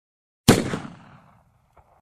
Magnum.ogg